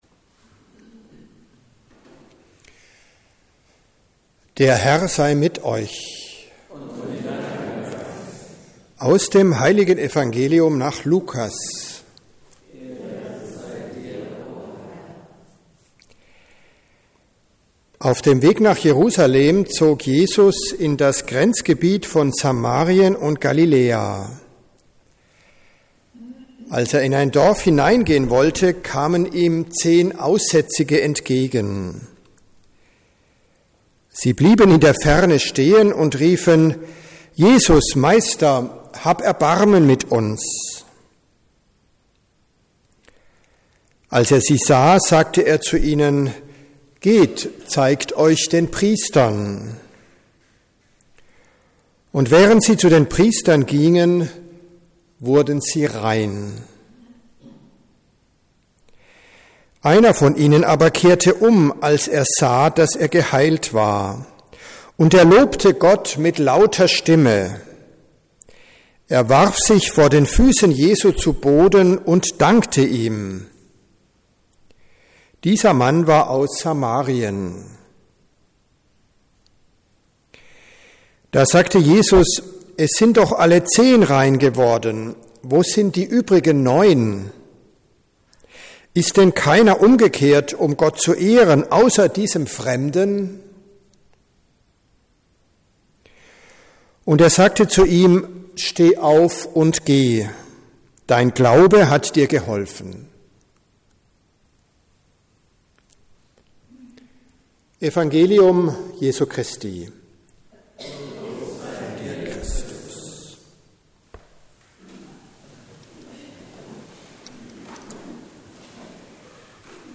Predigten (Audio) Predigt vom 21.